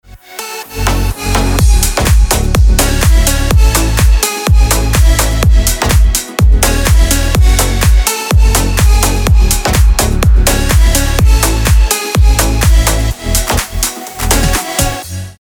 • Качество: 320, Stereo
Electronic
EDM
Club House
без слов
ремиксы